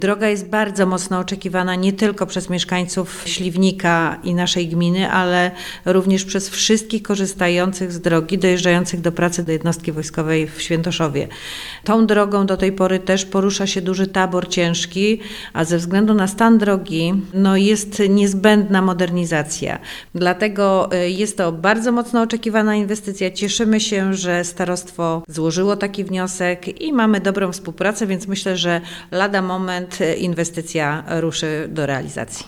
– Modernizacja tej drogi jest bardzo potrzebna – powiedziała Małgorzata Sendecka, burmistrz Małomic: